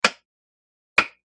SwitchesBreakerOld PE263801
Switches; Breaker On / Off 1; Older Breaker Style Switch Being Turned On And Then Off Again; Medium Perspective.